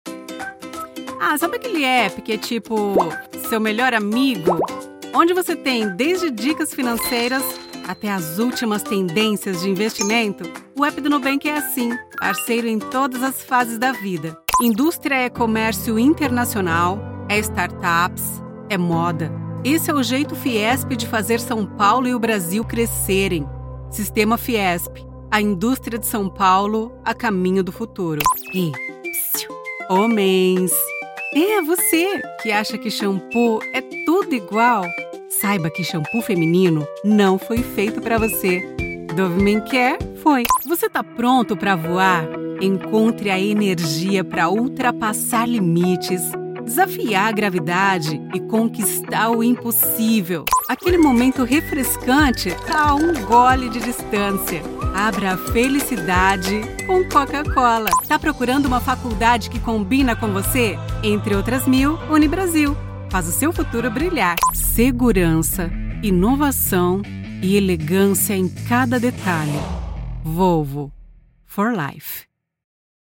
Narration
Vidéos explicatives
Apprentissage en ligne
Her voice is pleasant and versatile and can help you create a unique and captivating listening experience for your audience.
I have a professional home studio with all the bells and whistles.